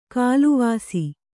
♪ kāluvāsi